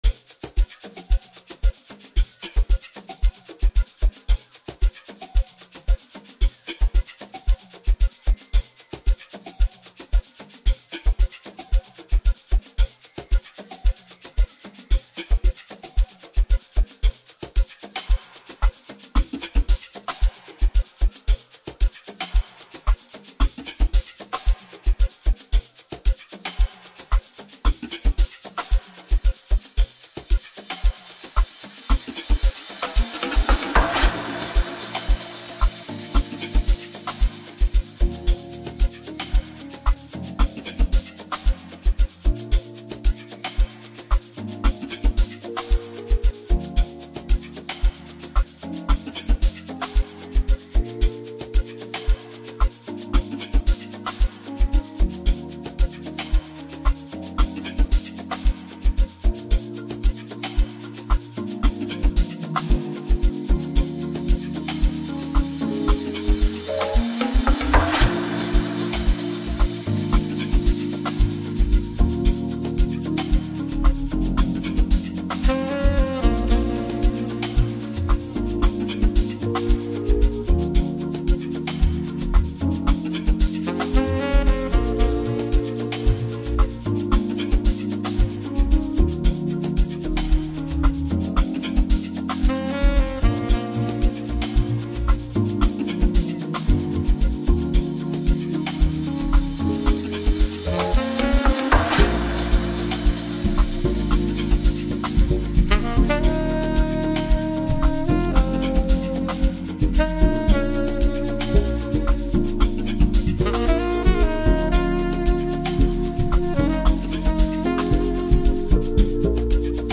Get ready to groove to the infectious beats